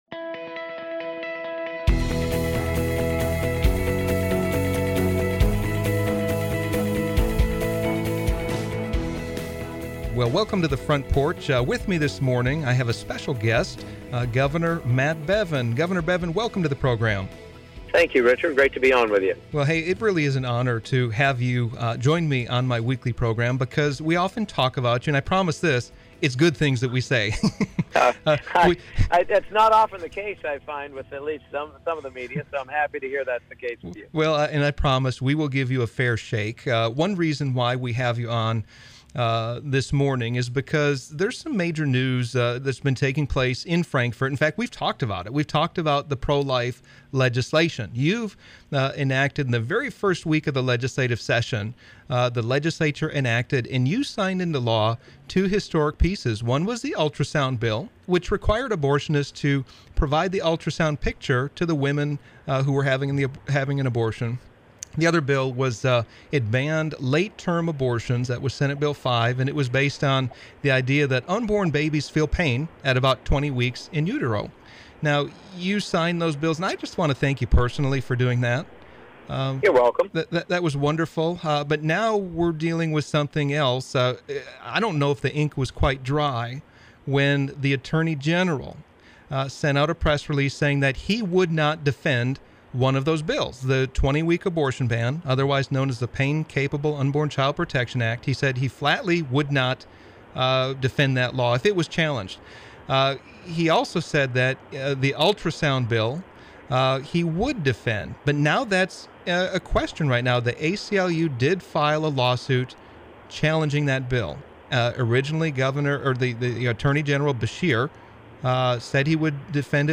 A Conversation with Governor Matt Bevin